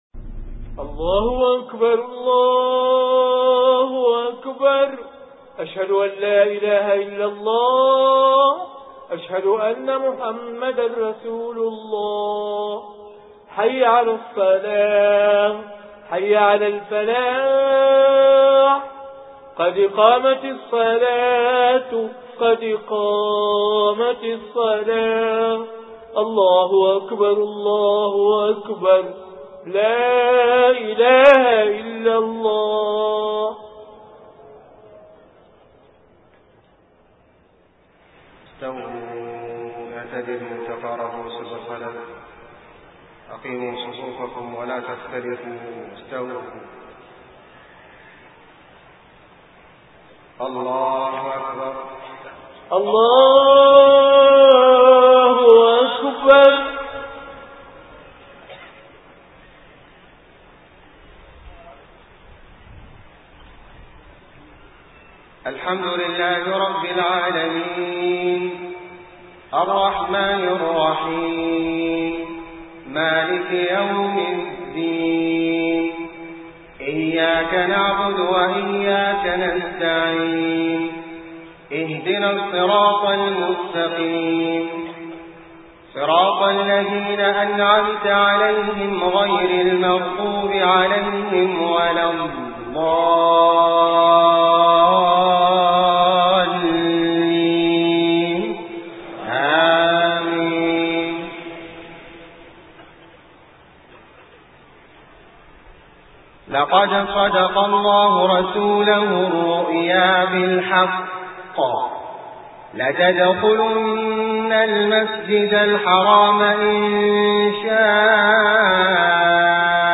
صلاة المغرب 30 محرم 1429هـ خواتيم سورة الفتح 27-29 > 1429 🕋 > الفروض - تلاوات الحرمين